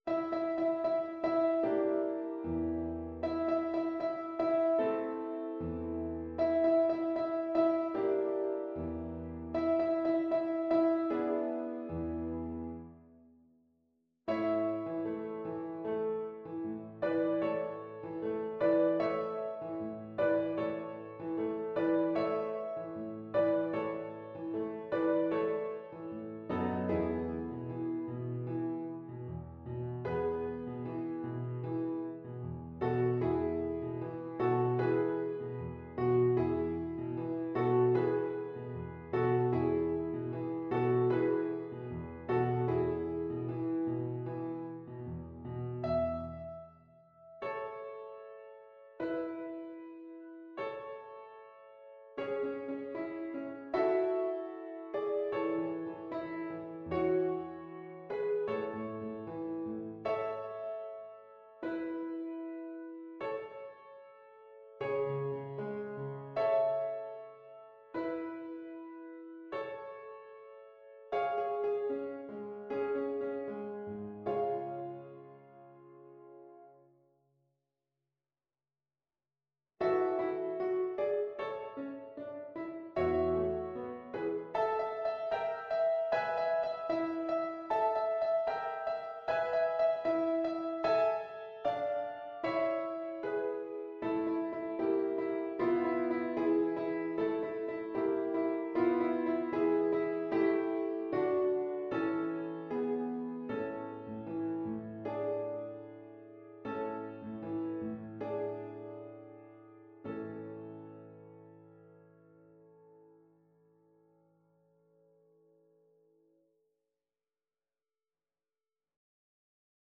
Play (or use space bar on your keyboard) Pause Music Playalong - Piano Accompaniment Playalong Band Accompaniment not yet available reset tempo print settings full screen
=76 Allegretto lusinghiero =104
A major (Sounding Pitch) (View more A major Music for Viola )
Classical (View more Classical Viola Music)